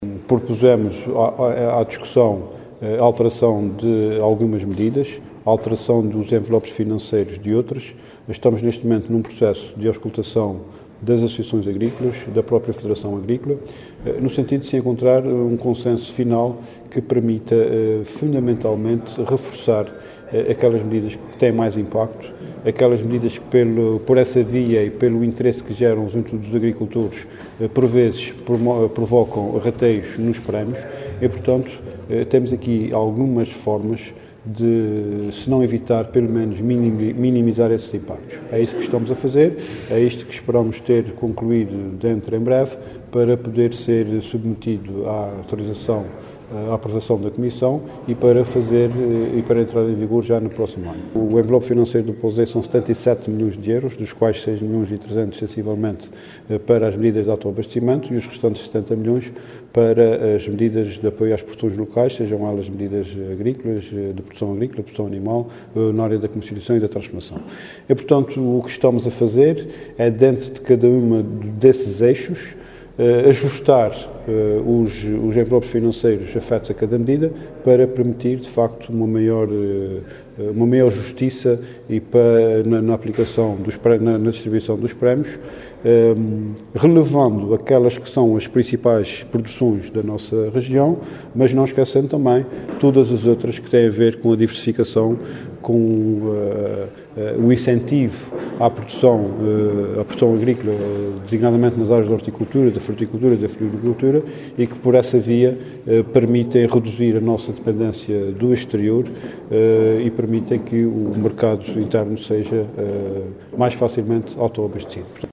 Para o Secretário Regional, que falava no final de uma audição na Comissão de Economia da Assembleia Legislativa, que vai elaborar um contributo para a revisão deste programa, importa “fundamentalmente reforçar aquelas medidas que têm mais impacto” e “pelo interesse que geram junto dos agricultores”.